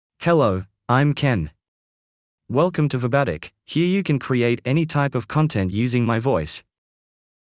MaleEnglish (Australia)
Ken is a male AI voice for English (Australia).
Voice sample
Listen to Ken's male English voice.
Male
Ken delivers clear pronunciation with authentic Australia English intonation, making your content sound professionally produced.